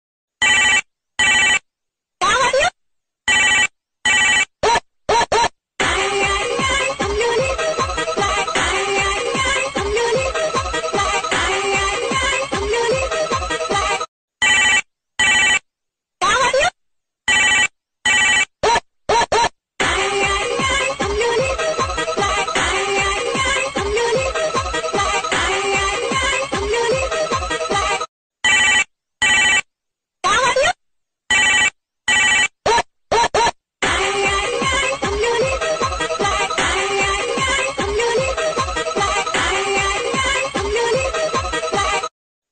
Genre: Nada dering panggilan
nada ini nostalgik, khas HP mainan anak